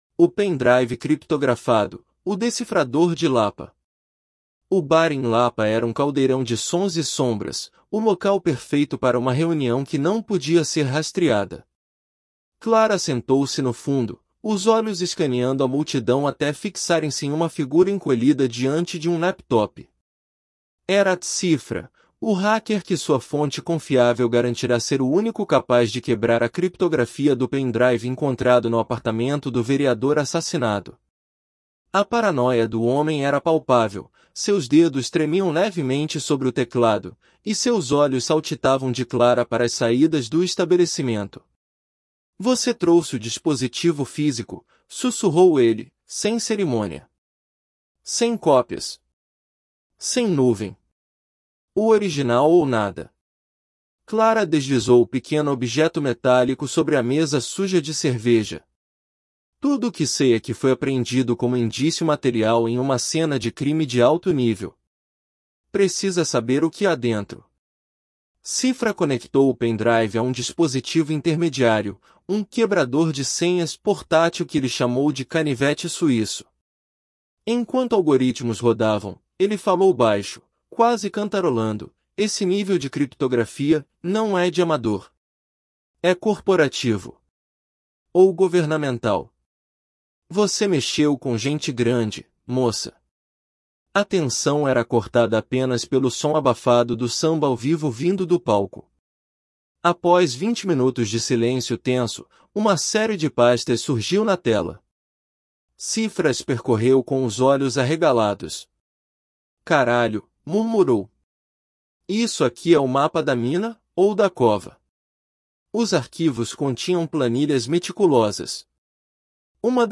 • Before reading: Listen to understand rhythm, intonation, and natural speech.